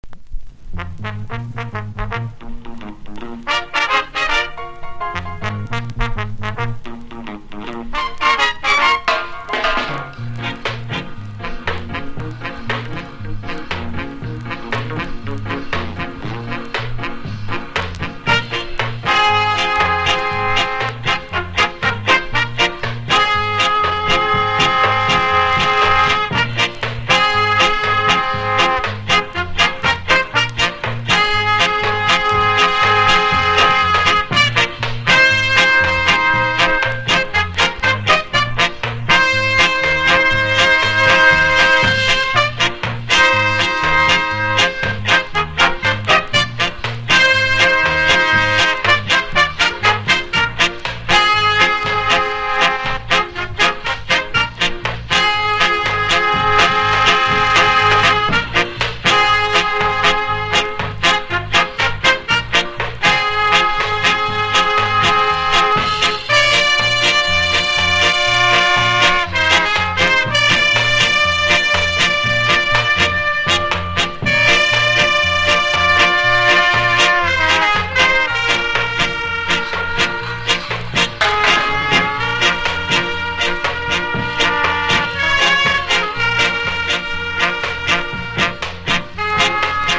ジャズ的要素のホーン隊がタイトなパーカッションと合わさってラテン的哀愁を感じさせてくれる楽曲も収録。